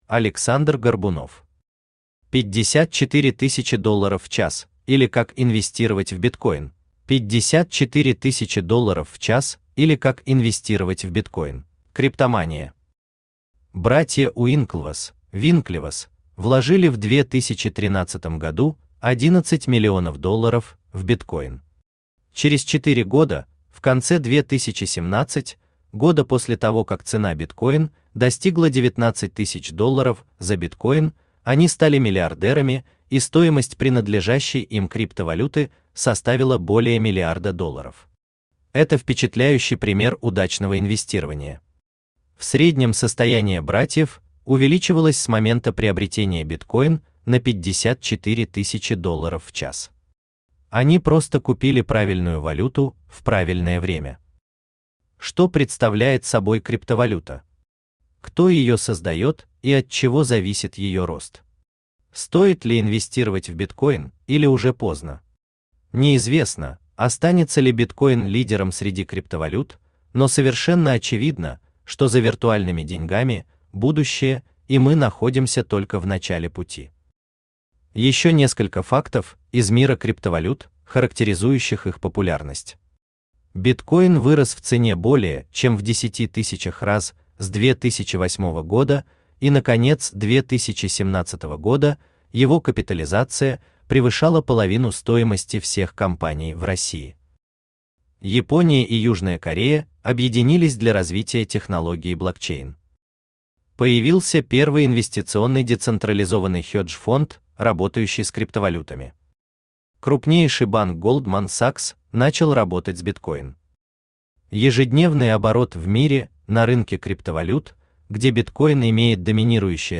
Аудиокнига 54000$ в час или как инвестировать в Bitcoin?